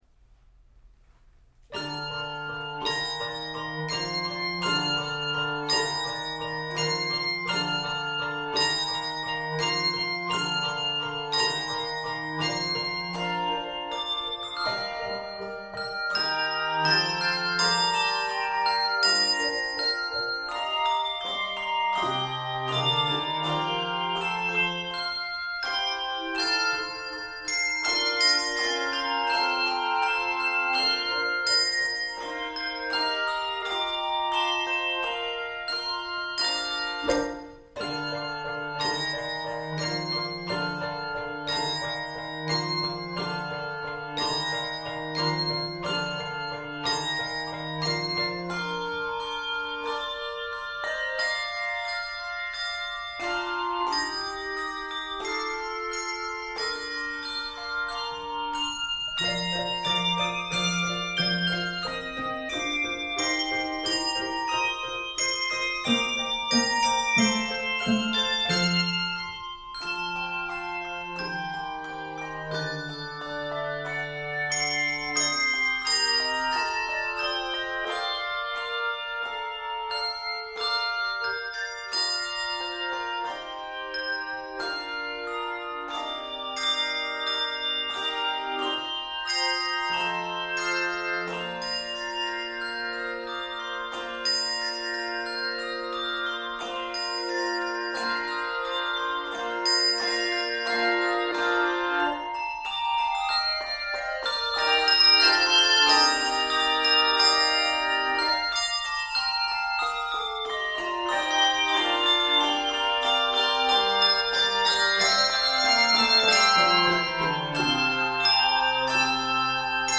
Key of Bb Major.